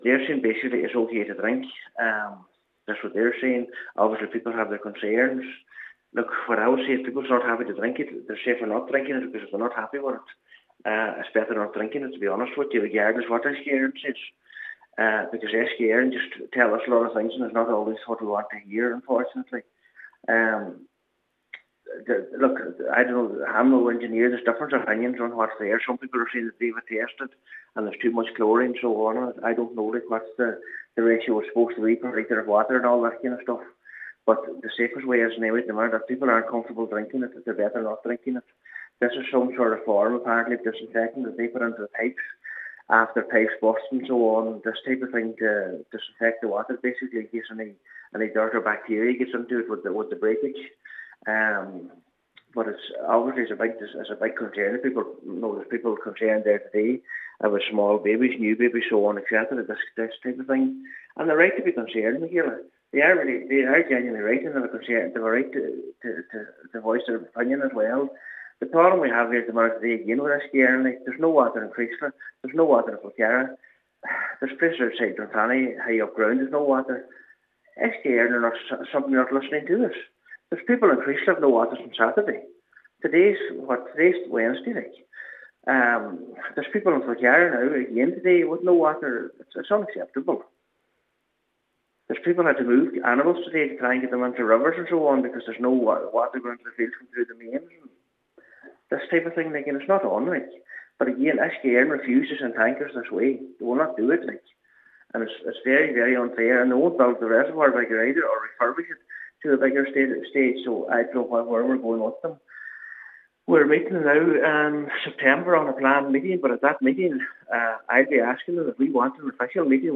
Cathaoirleach of the Glenties Municipal District, Councillor Michael McClafferty, while welcoming Uisce Eireann’s statement says this is just another example of the water infrastructure issues that continues to plague Donegal: